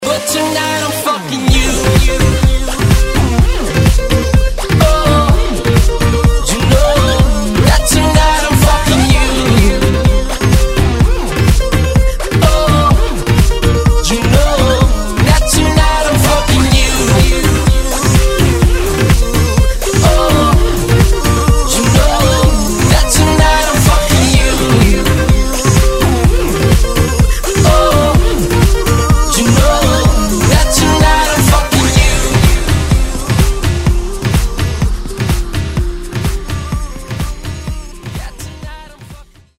Главная » Файлы » Клубные рингтоны